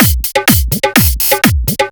125 BPM Beat Loops Download